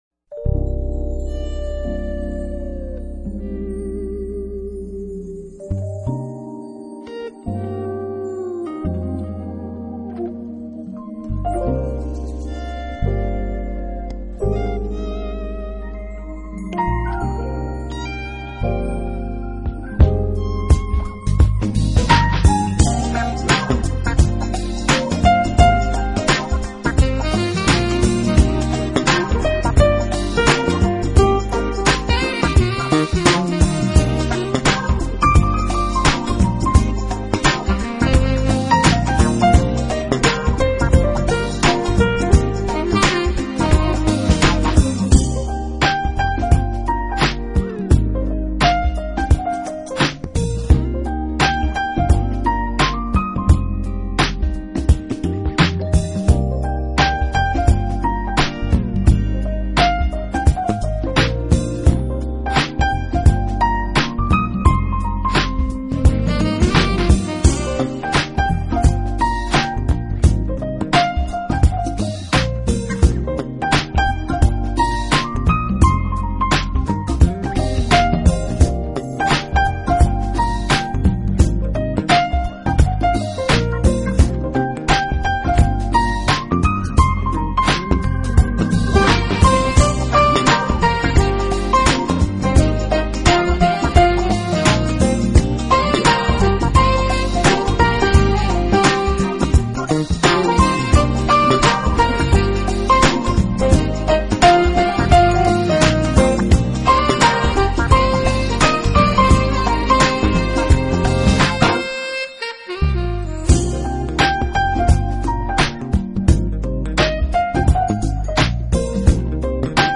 【爵士钢琴】
悠扬的吉它牵出清脆的钢琴，整个曲子弥漫着轻松与惬意，